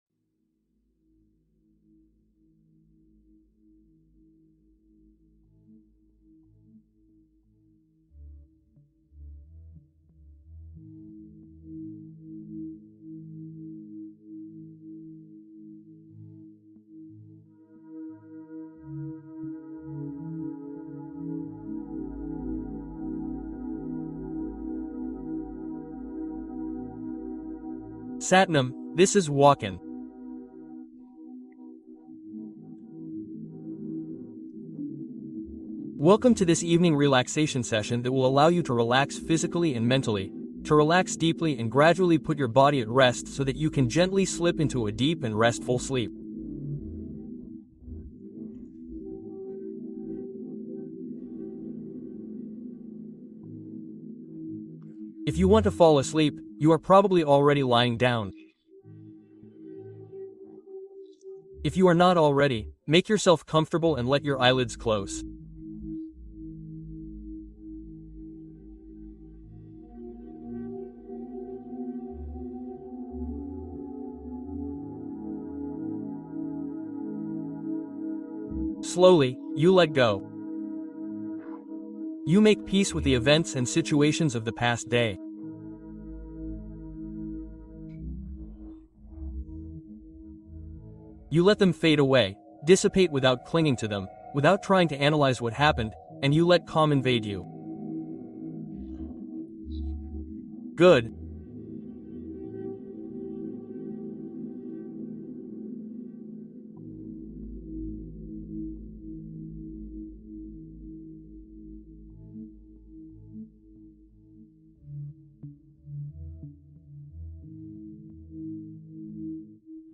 Repos Immédiat : Relaxation profonde pour un endormissement fluide